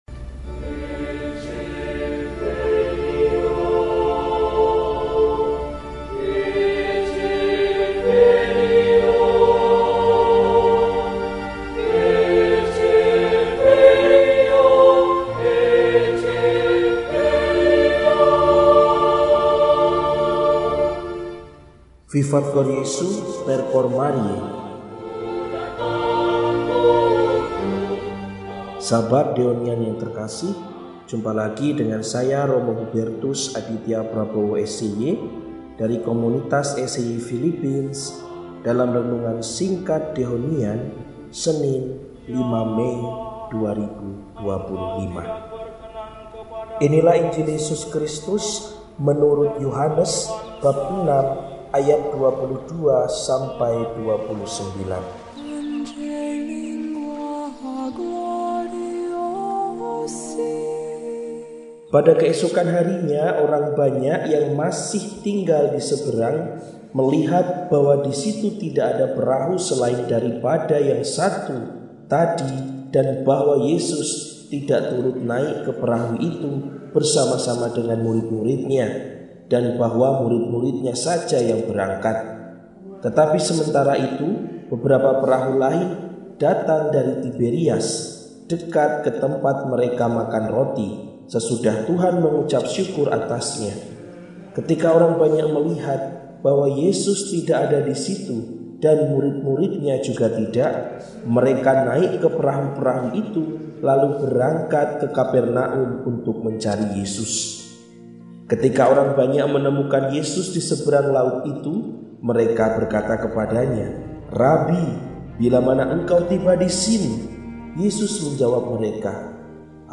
Senin, 05 Mei 2025 – Hari Biasa Pekan III Paskah – RESI (Renungan Singkat) DEHONIAN